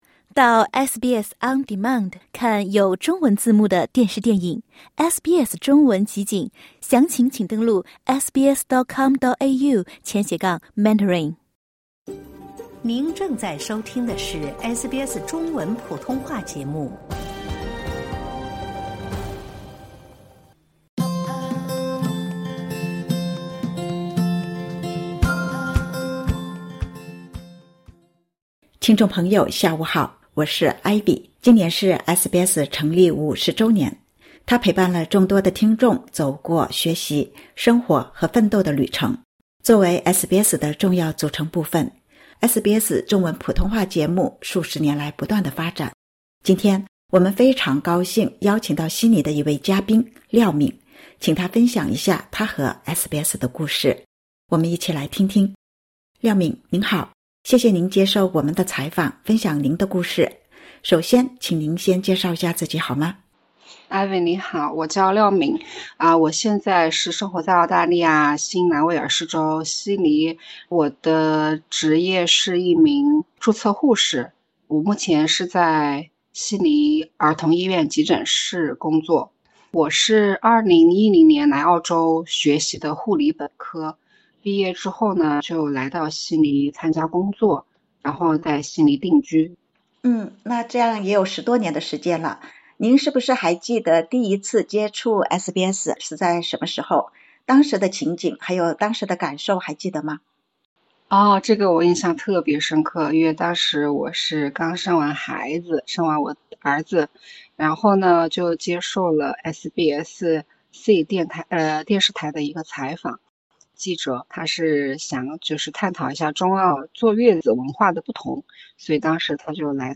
日前，她接受SBS中文普通话节目采访，讲述她和SBS的故事。